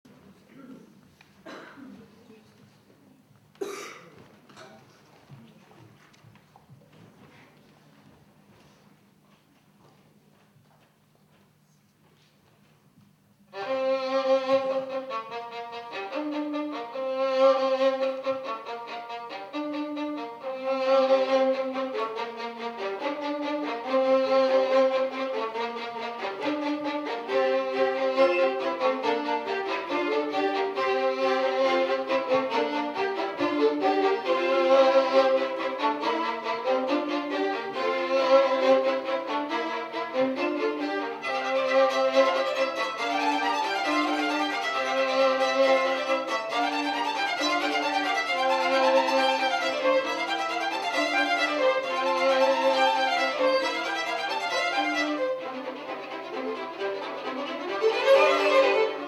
I Mozart Boys&Girls in concerto - Sabato 21 febbraio 2009 - Z. Milenkovic - Danza delle fate